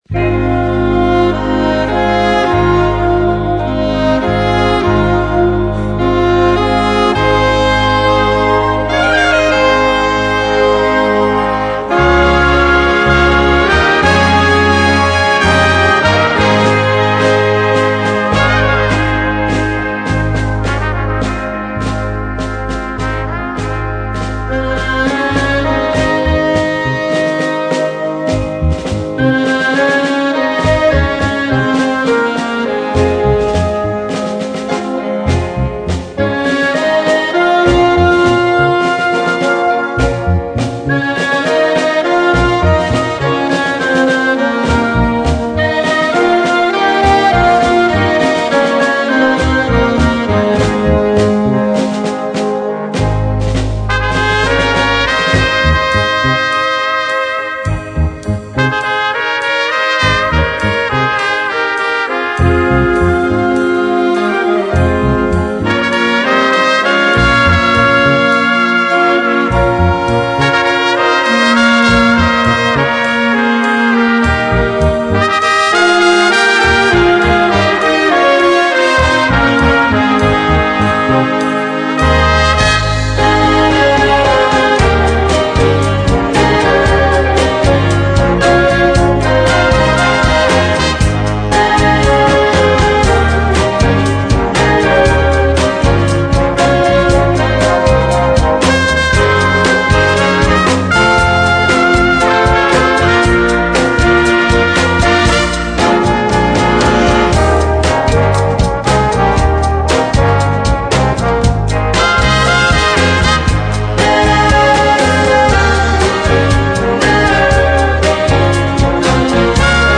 Gattung: Moderner Einzeltitel
Besetzung: Blasorchester